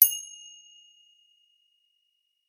finger_cymbals_side01
bell chime cymbal ding finger-cymbals orchestral percussion sound effect free sound royalty free Sound Effects